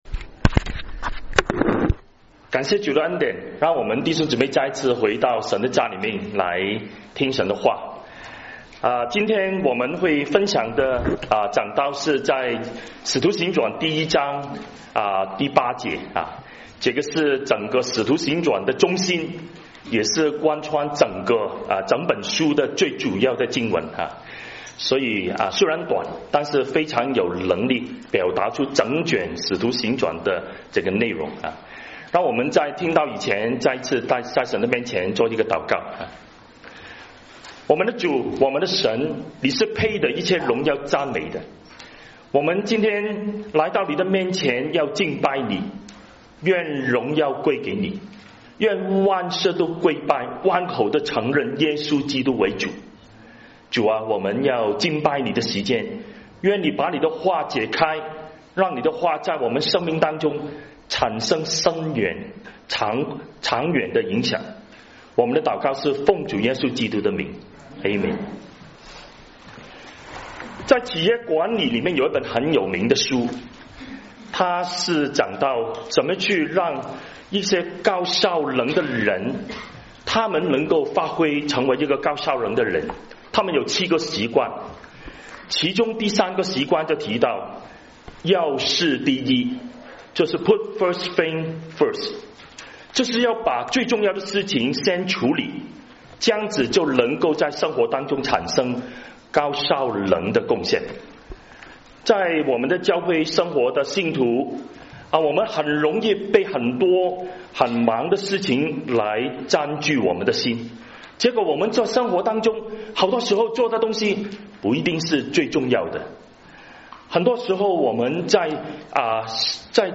波士頓華人佈道會